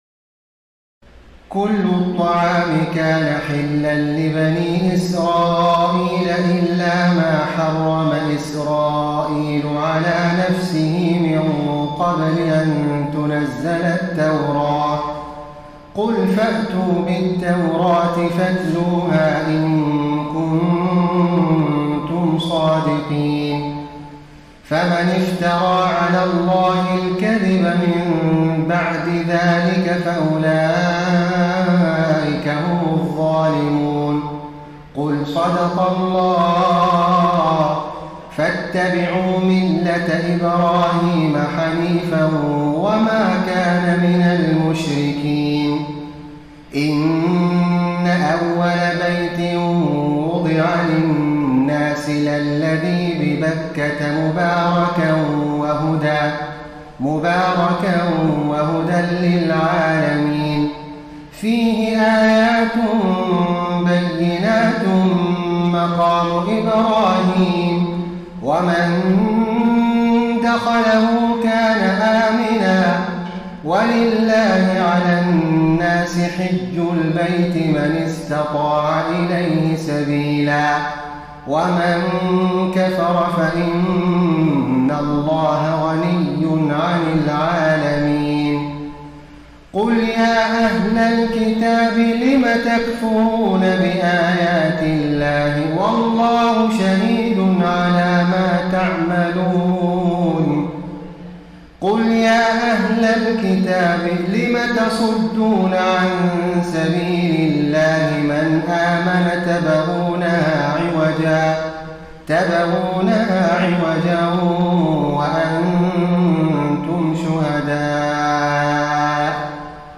تراويح الليلة الرابعة رمضان 1433هـ من سورة آل عمران (93-168) Taraweeh 4 st night Ramadan 1433 H from Surah Aal-i-Imraan > تراويح الحرم النبوي عام 1433 🕌 > التراويح - تلاوات الحرمين